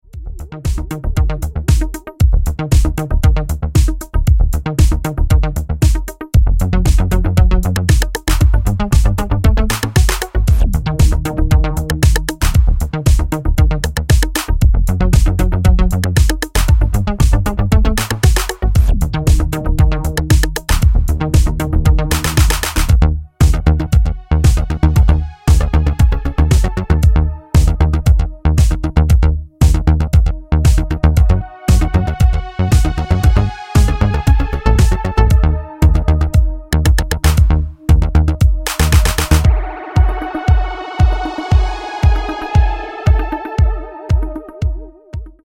dark italo / electro techno moderne